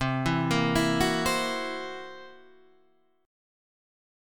C 7th Flat 5th